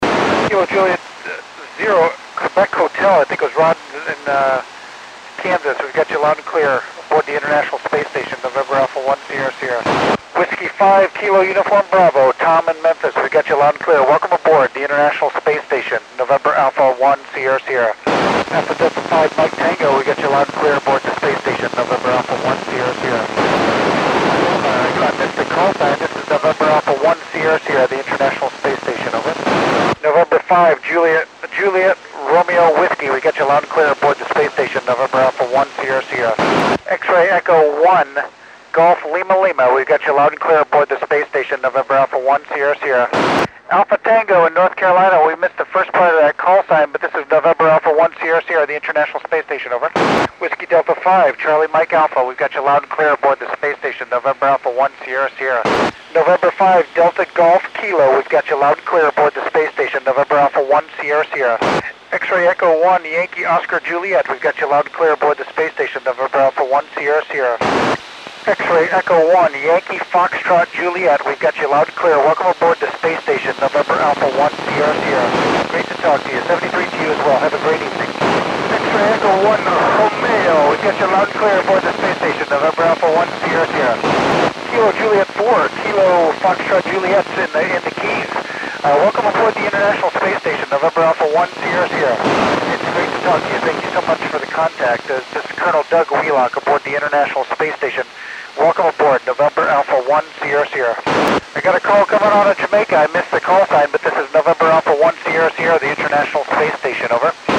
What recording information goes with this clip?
It was a 10 degree pass, so kind of scratchy.